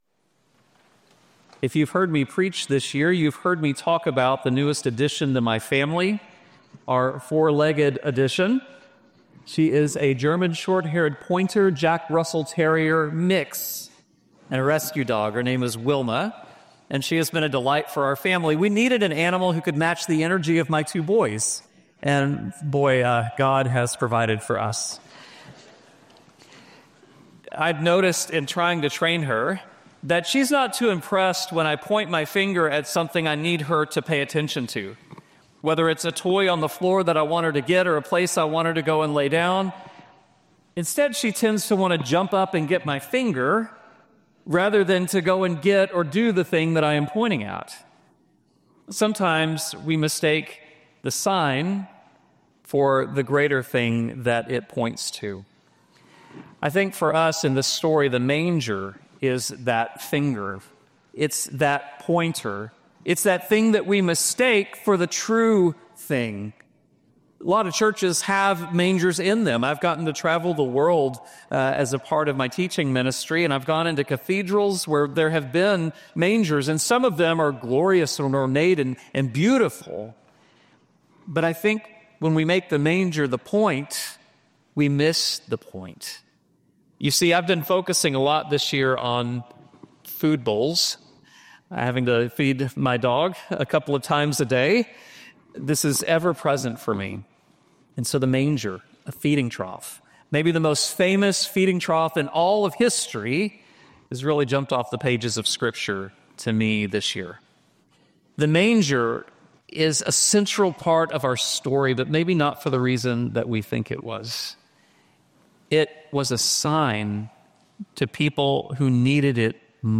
Service Type: Traditional